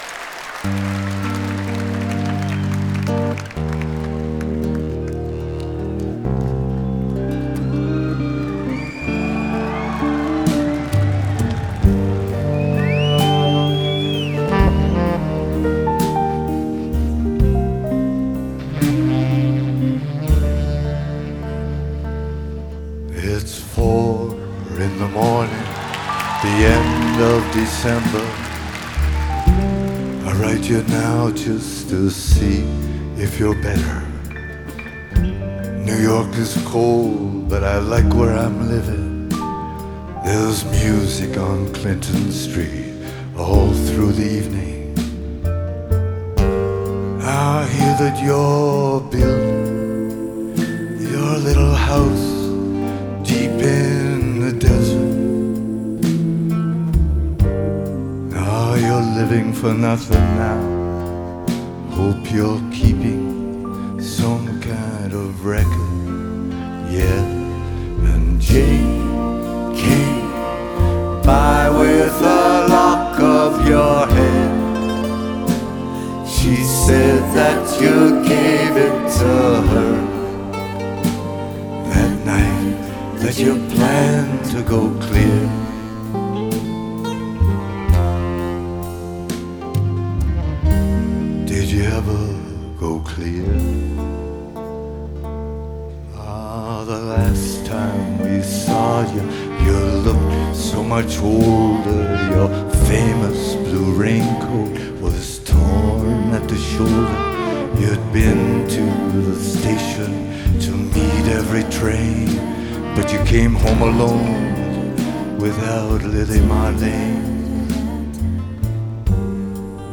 блюз
рок-музыка